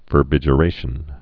(vər-bĭjə-rāshən)